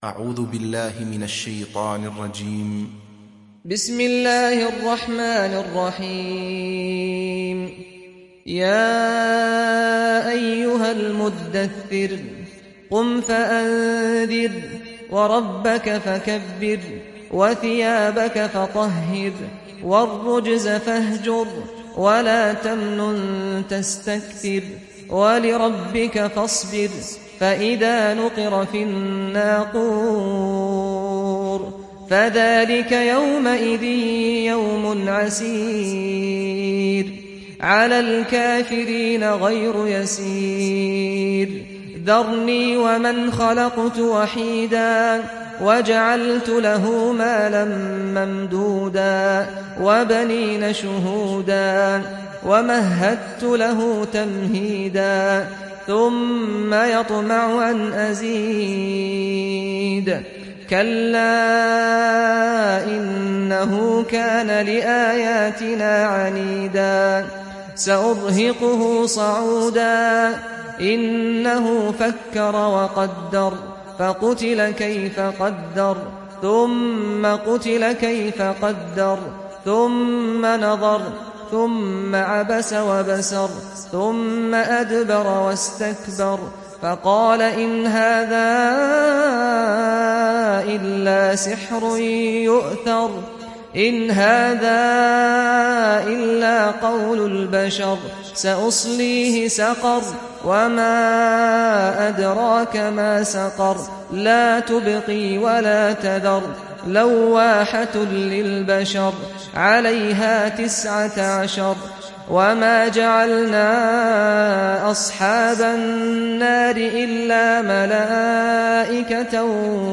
دانلود سوره المدثر mp3 سعد الغامدي روایت حفص از عاصم, قرآن را دانلود کنید و گوش کن mp3 ، لینک مستقیم کامل